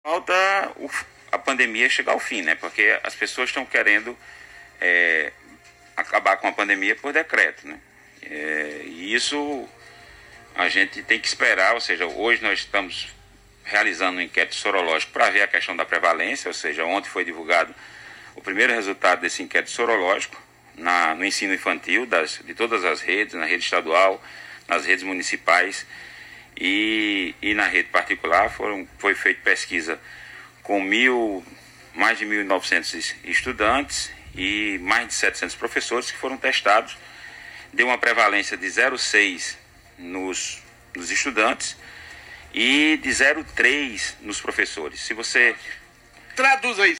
Durante uma entrevista na tarde desta sexta-feira (12), o Secretário de Educação, Professor Claudio Furtado, falou a cerca da volta 100% das aulas presenciais na Paraíba. O secretário disse que estão acontecendo pesquisas de estudo para essa volta e enfatizou que isso só irá acontecer quando a pandemia acabar.